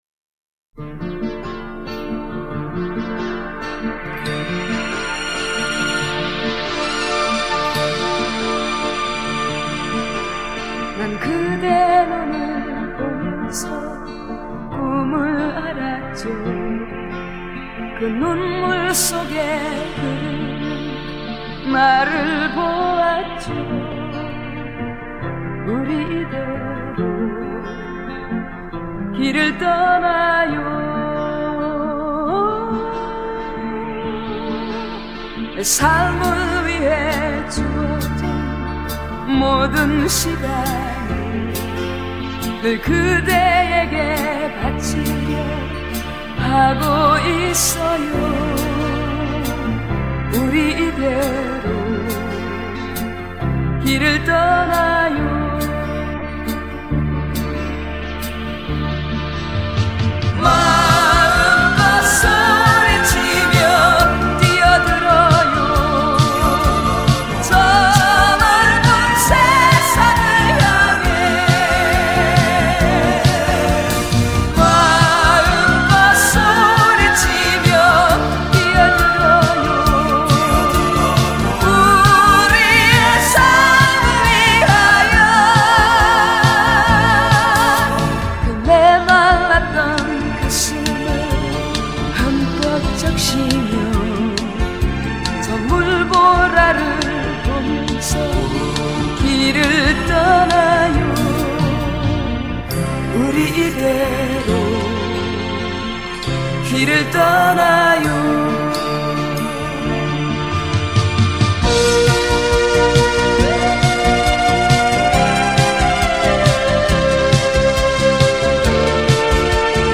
1980년대를 대표하는 여성 가수